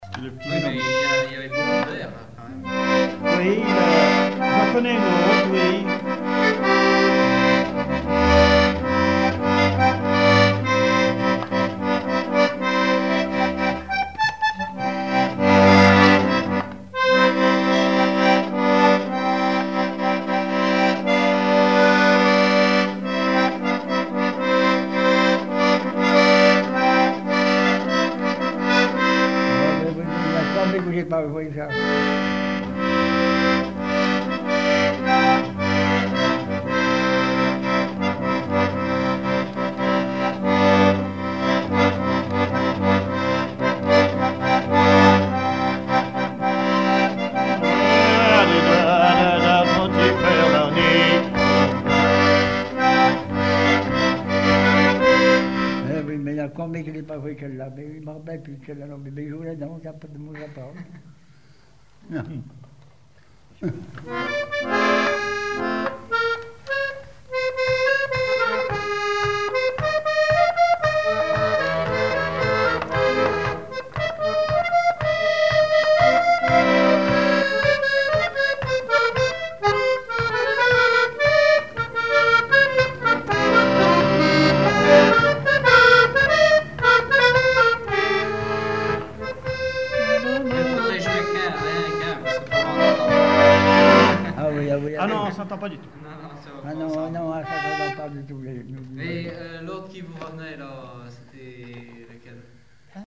Fonction d'après l'analyste gestuel : à marcher
Genre laisse
chansons populaires et instrumentaux
Pièce musicale inédite